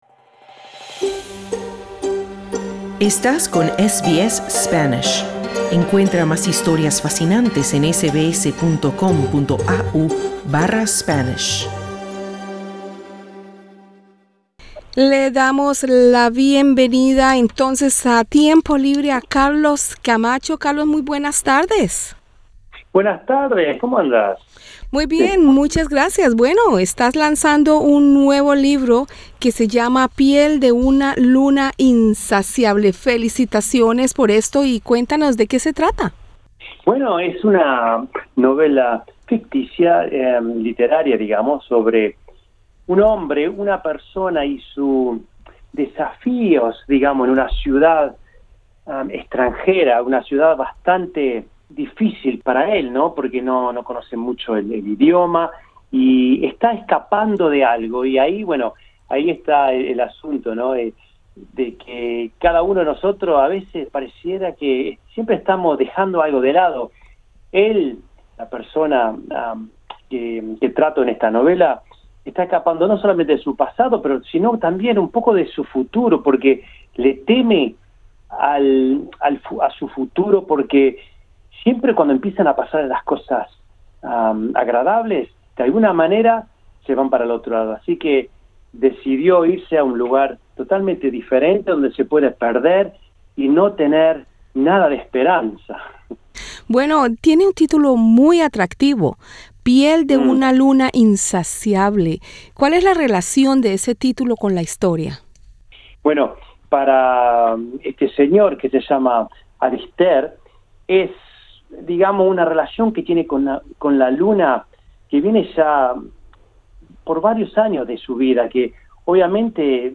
Escucha la entrevista con el escritor, en nuestro podcast.